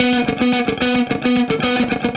AM_HeroGuitar_110-B01.wav